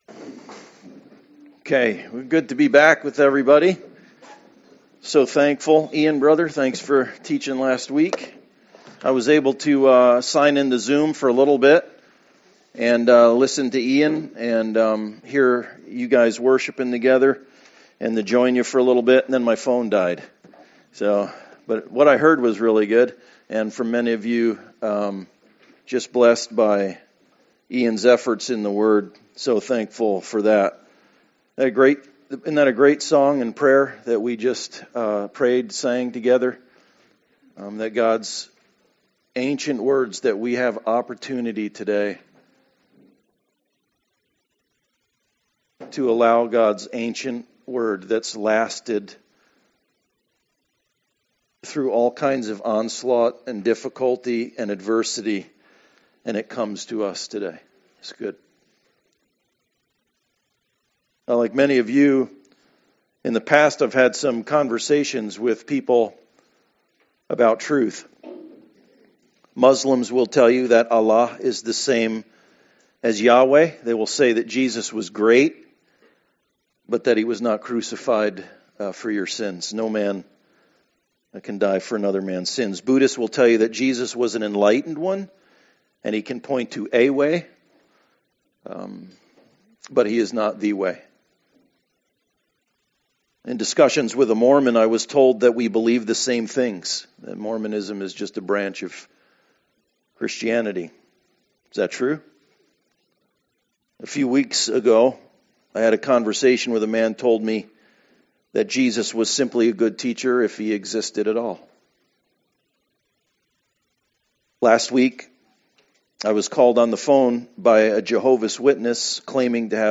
True or False Passage: 1 John 4:1-6 Service Type: Sunday Service True or False Download Files Notes « Prepper True or False »